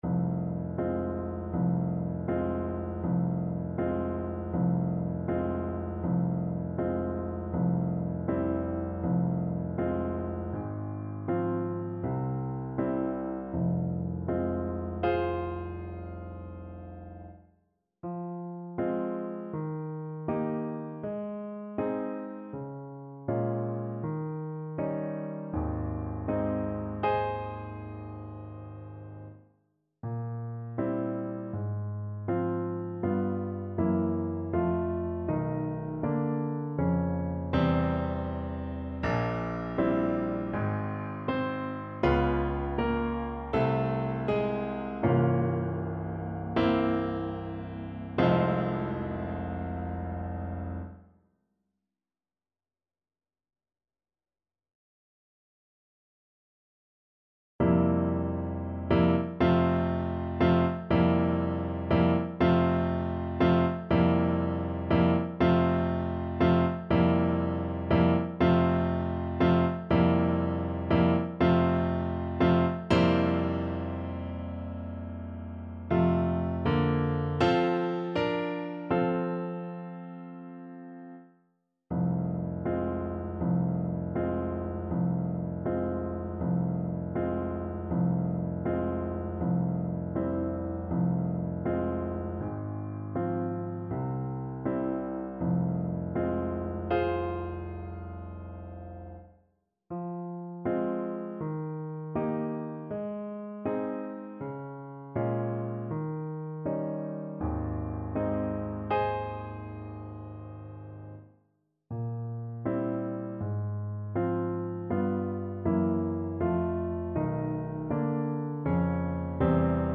• Unlimited playalong tracks
Un poco andante
Classical (View more Classical Trumpet Music)